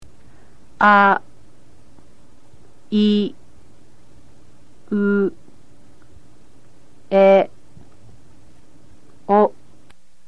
In this lesson you will learn the Hiragana form of the sounds A, I, U, E, O. To see the stroke order, all you need to do is hover your mouse over the graphic of the character shown on the page. To hear how to pronounce the characters, click your mouse on any picture of a character group.